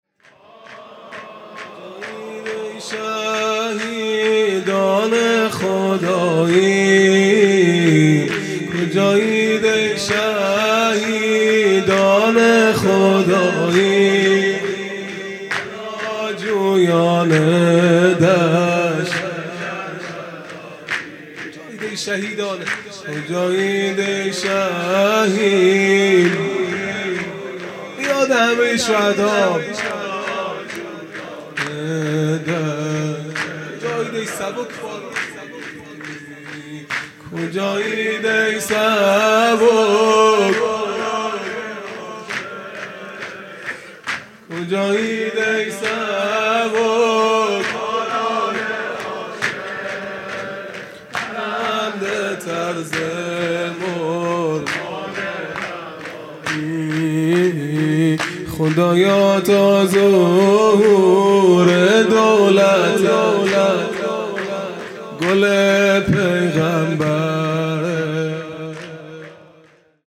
خیمه گاه - هیئت بچه های فاطمه (س) - شور پایانی | کجایید ای شهیدان خدایی
عزاداری فاطمیه اول | شب اول